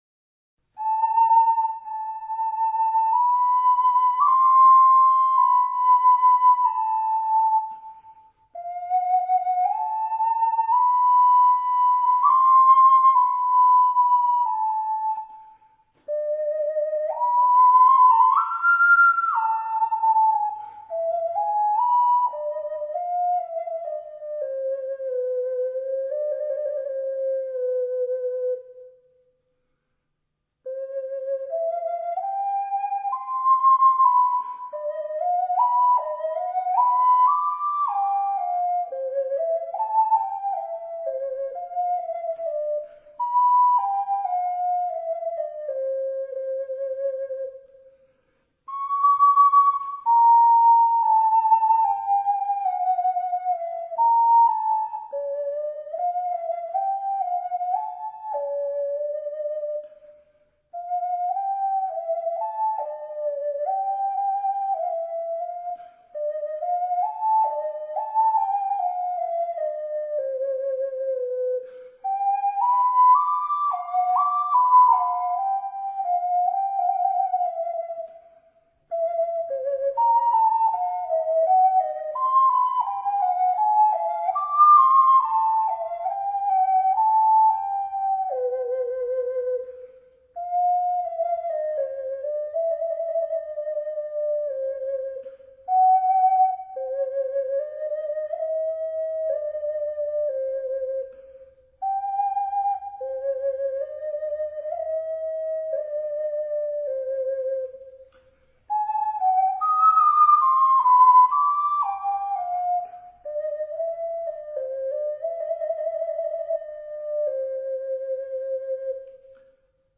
【Ocarina Room】